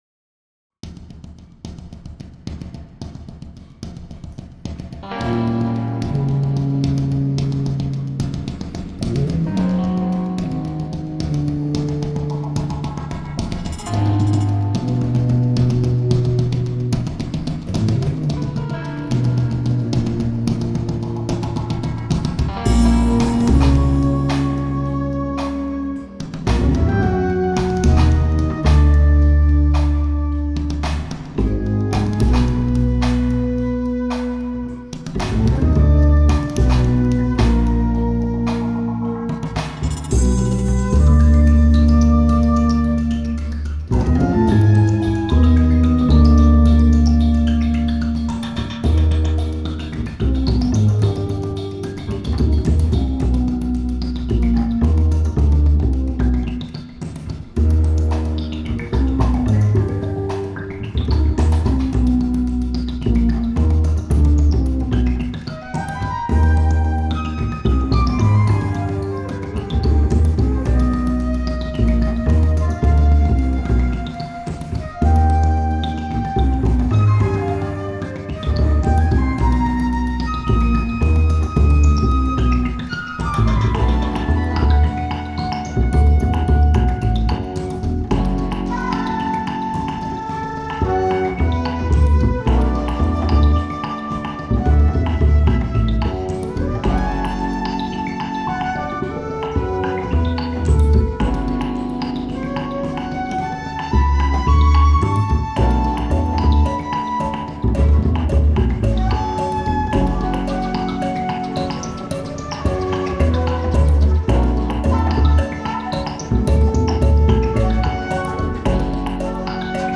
À écouter au casque 🎧 (format binaural)
Tubular-Binaural-V2.wav